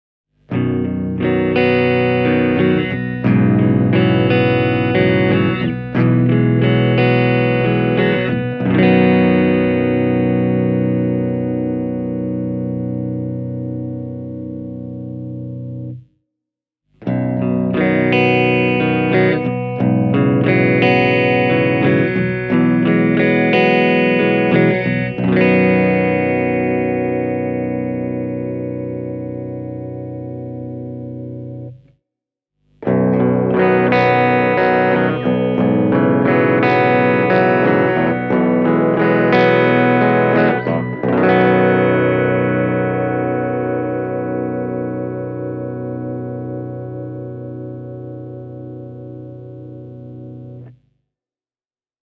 The Jericho Fusion (price in Finland approximately 999 €) is a sleek and elegant baritone guitar, with a body outline that is based on the venerable Fender Telecaster.
The Jericho Fusion comes equipped with a classic pair of Seymour Duncan humbuckers – a ’59-model in the neck position, and a Custom TB-5 at the bridge.
Don’t worry, the Jericho Fusion can do the head-banging stuff with aplomb, too, but this guitar also holds very lush clean tones in store, should you be so inclined.
Here’s a short clip of all three toggle switch settings (starting at the neck), played through a clean amplifier: